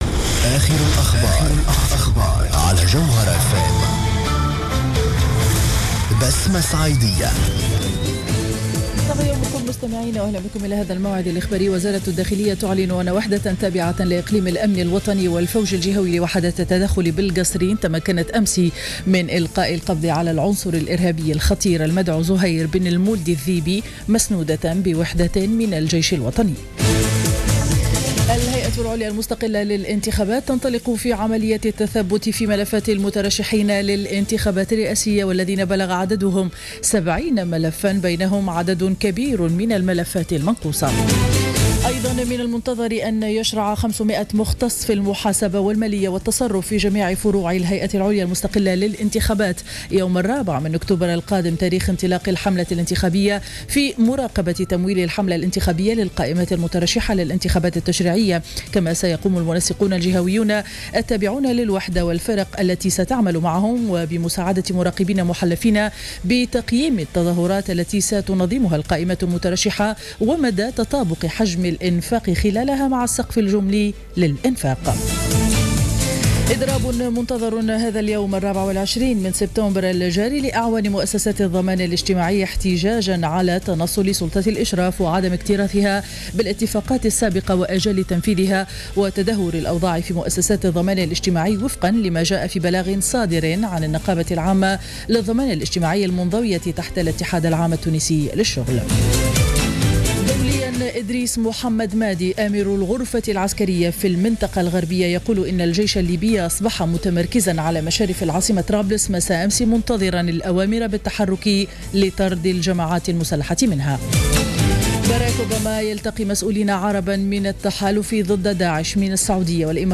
نشرة أخبار السابعة صباحا ليوم الاربعاء 24-09-14